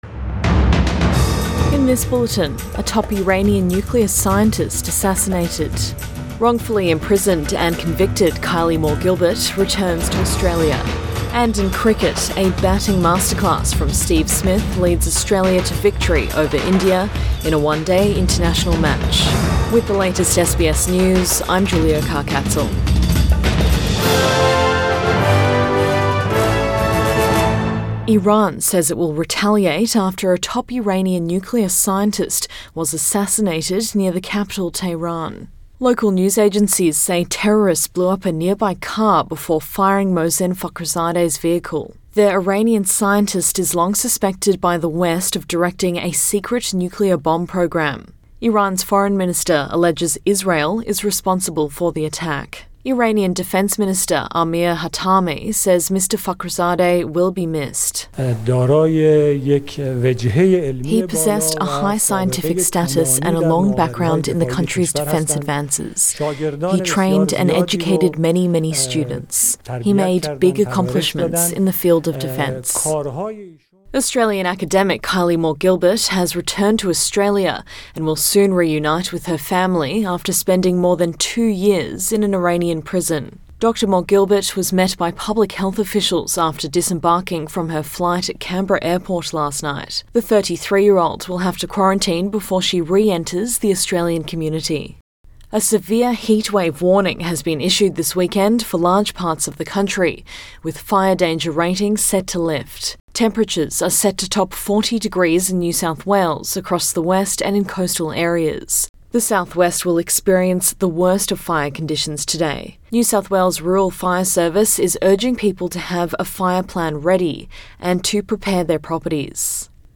AM bulletin 28 November 2020